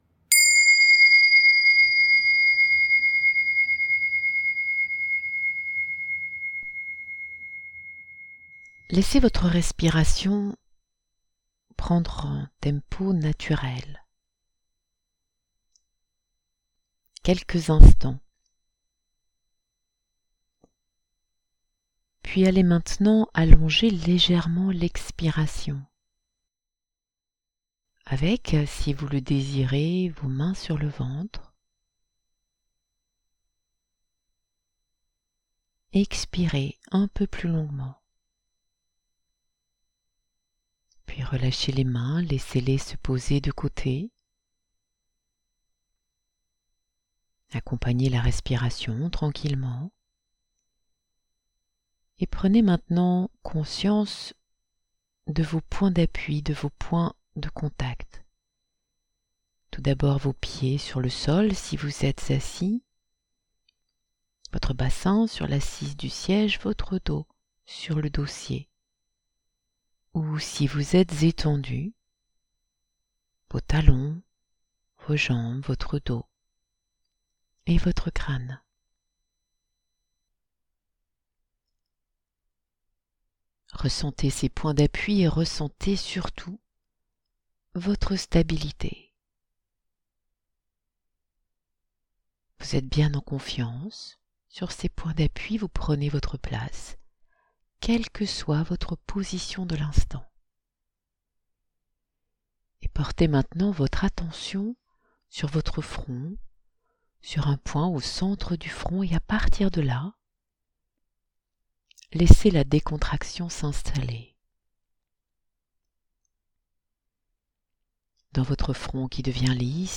Genre : Speech.